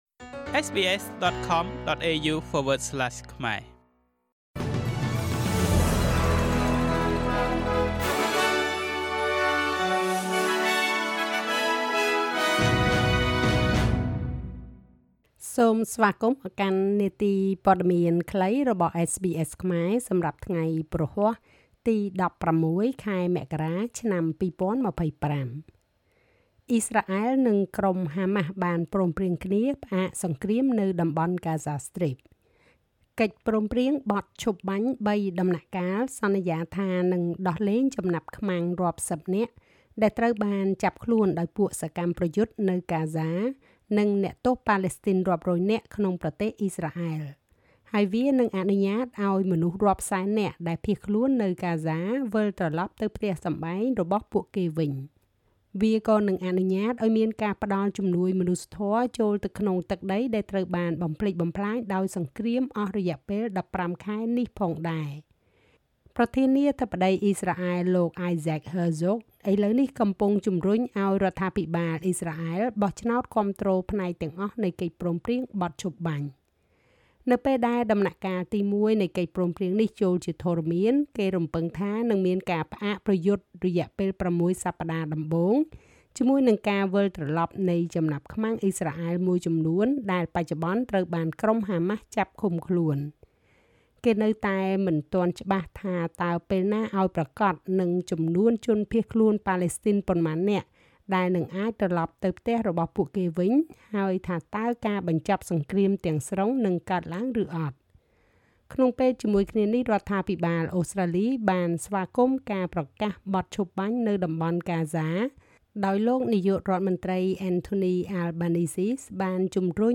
នាទីព័ត៌មានខ្លីរបស់SBSខ្មែរ សម្រាប់ថ្ងៃព្រហស្បតិ៍ ទី១៦ ខែមករា ឆ្នាំ២០២៥